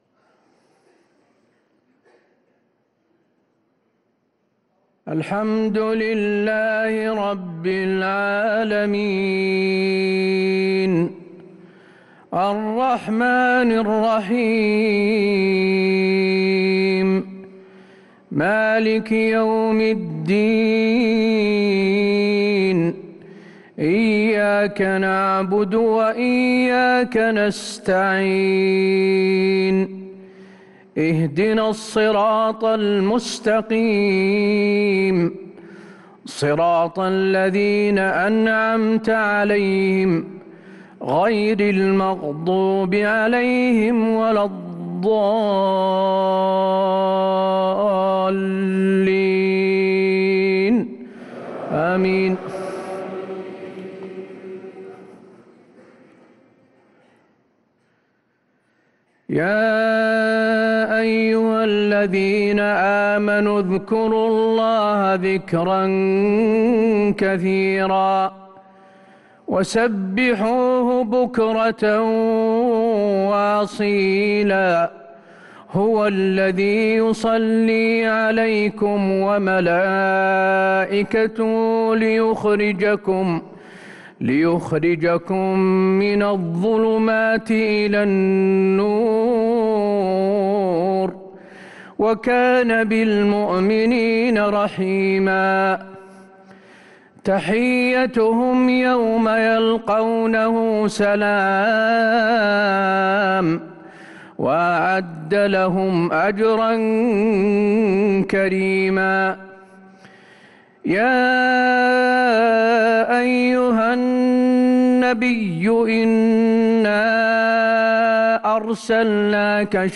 صلاة العشاء للقارئ حسين آل الشيخ 16 رمضان 1444 هـ